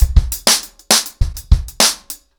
BellAir-A-100BPM__1.1.wav